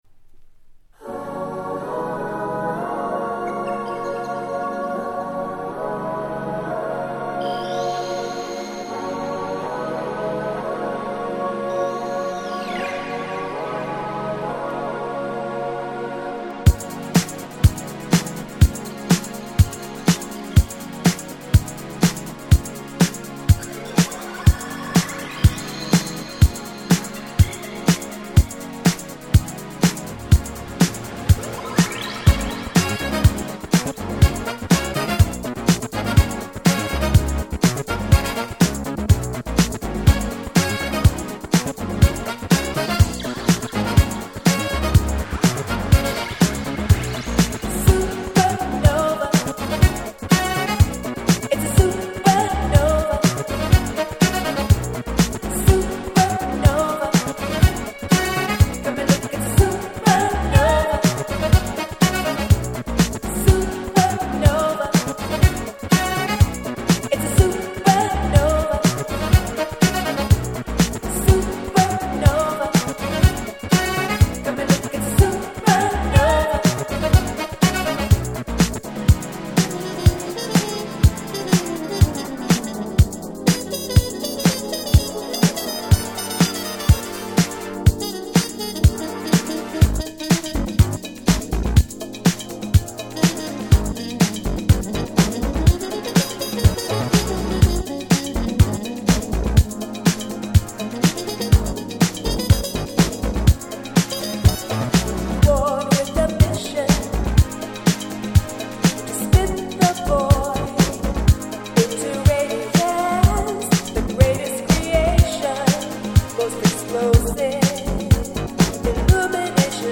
国産Nice Disco Boogie/Modern Soul !!